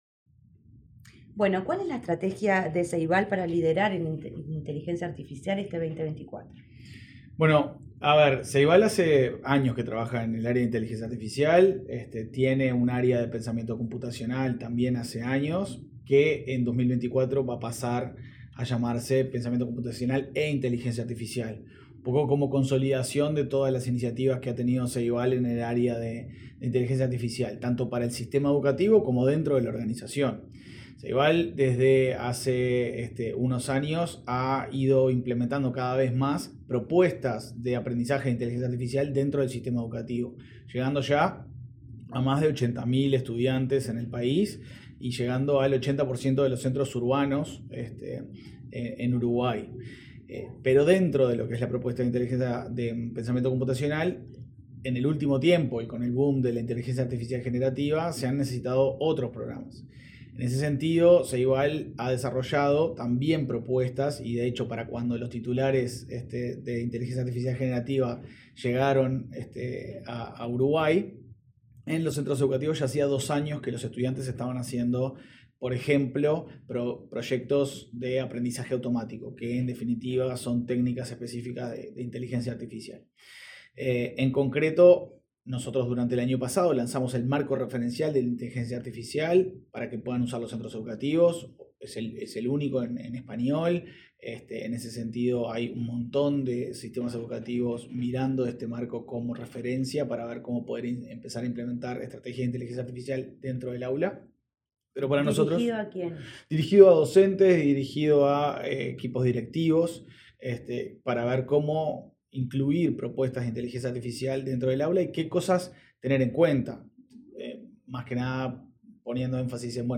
Entrevista al presidente de Ceibal, Leandro Folgar